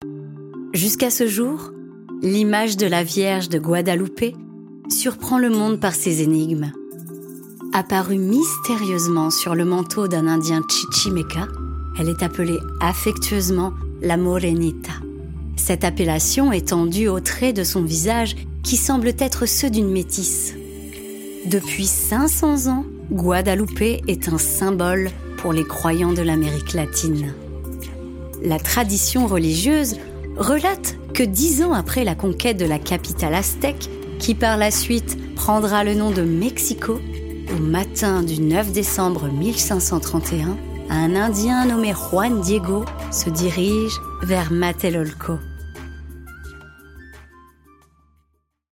Bandes-son
5 - 75 ans - Mezzo-soprano